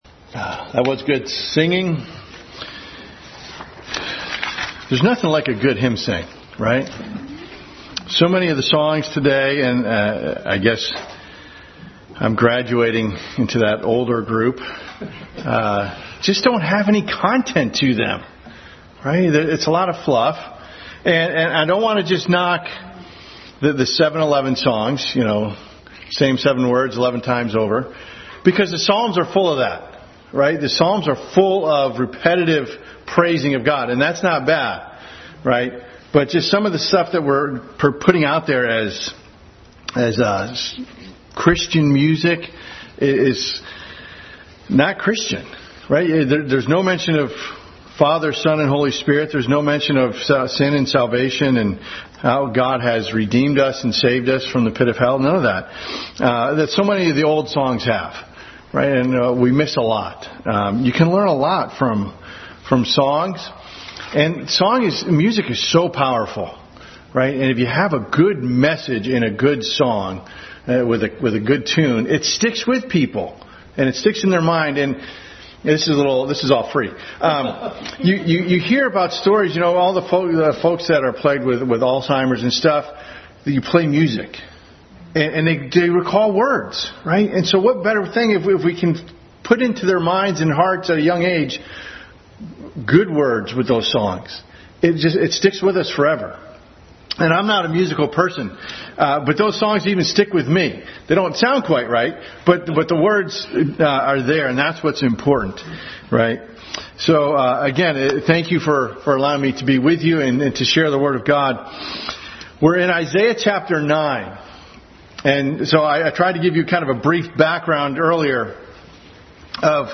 Passage: Isaiah 9:1-7, Exodus 32:12-13, Isaiah 42:8, Ezekiel 36:23-28, Romans 3:23 Service Type: Family Bible Hour Family Bible Hour message.